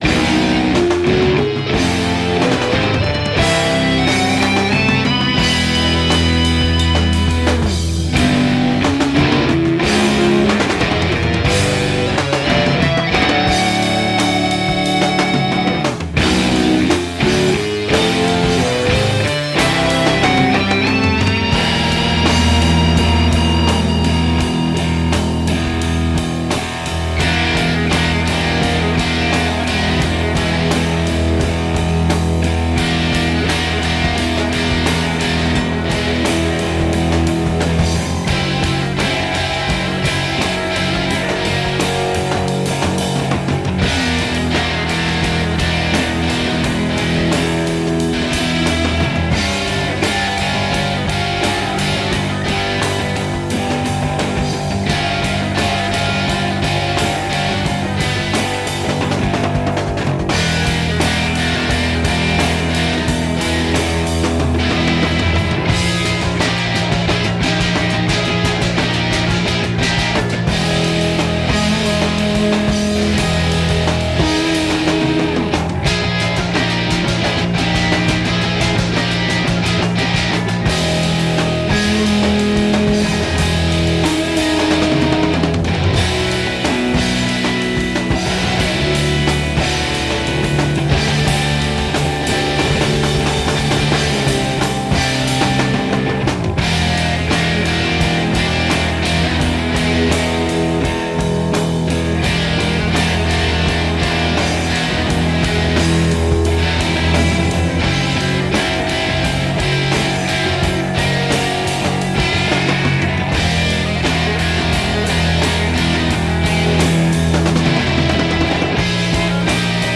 Progressive Rock